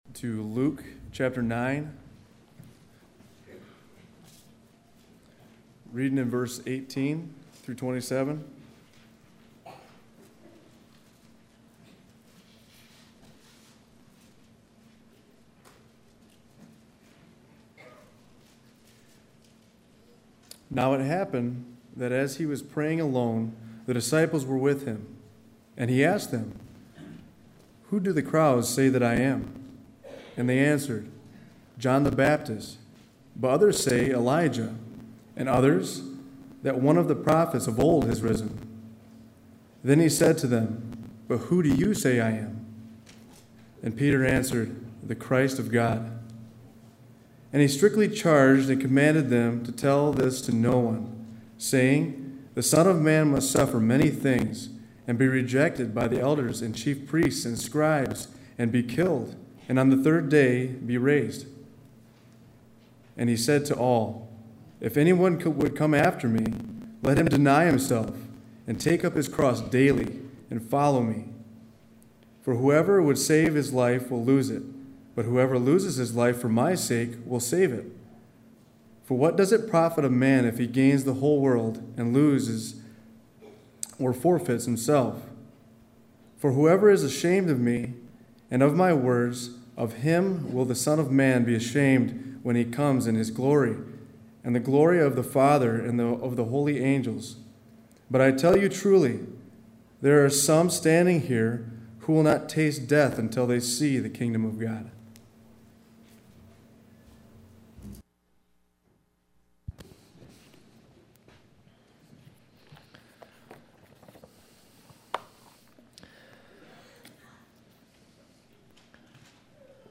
Sermons | Rothbury Community Church